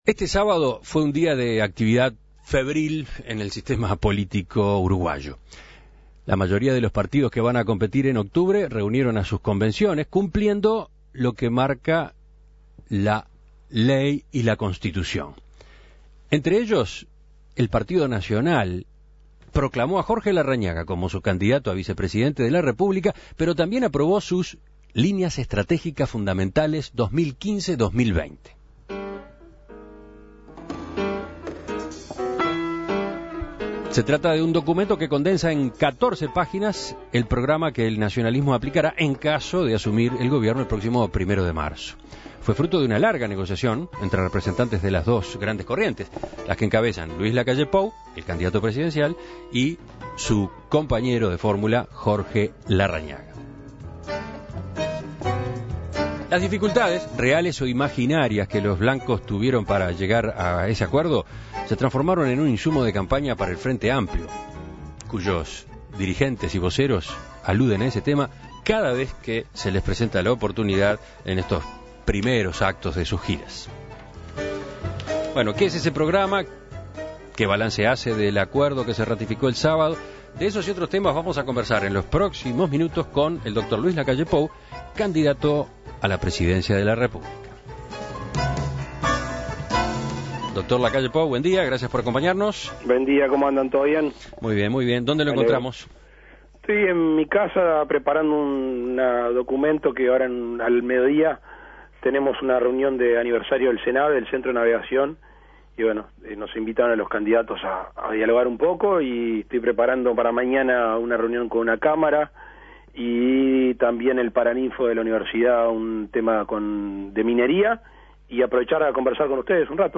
En Perspectiva entrevistó a Luis Lacalle Pou para repasar esos puntos de discrepancia y entender cómo lograron resolverlos.